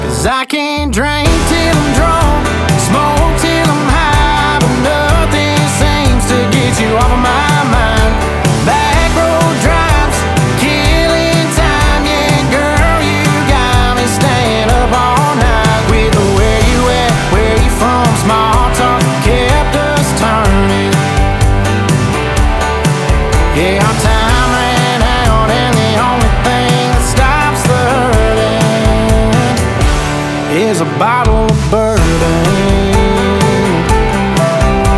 Скачать припев, мелодию нарезки
2025-03-07 Жанр: Кантри Длительность